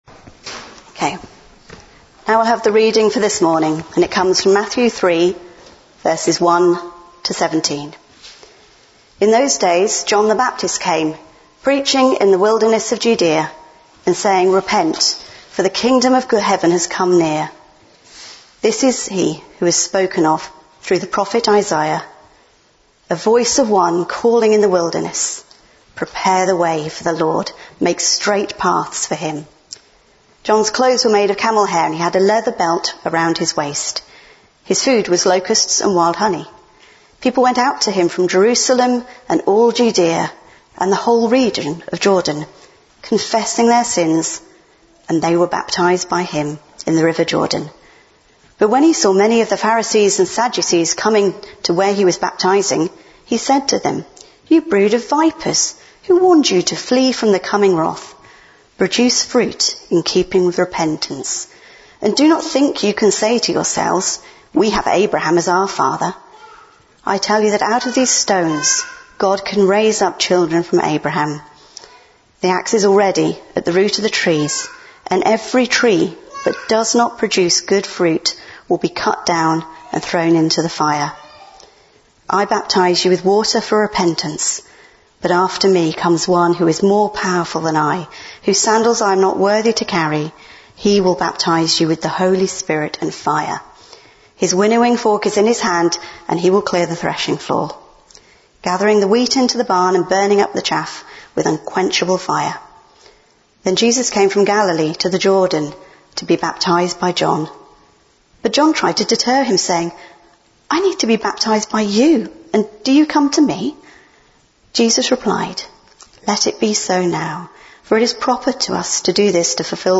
Bluntisham Baptist Church Sermons